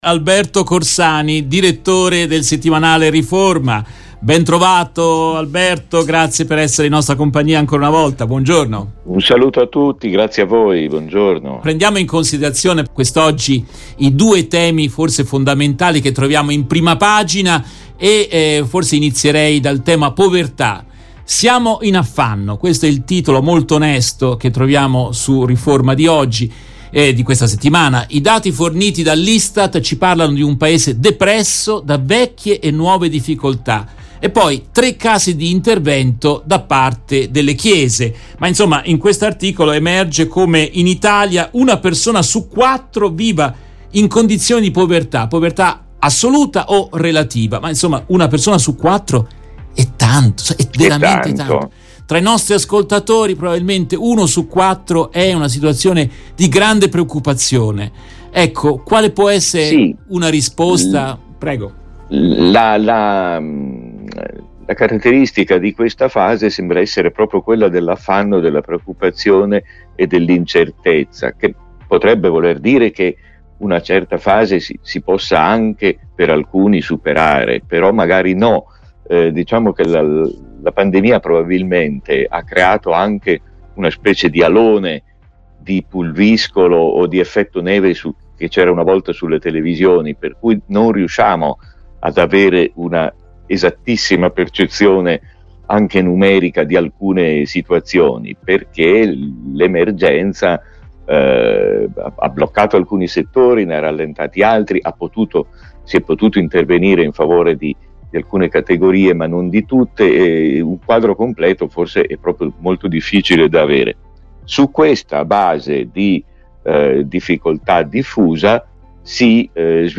Continuano le conversazioni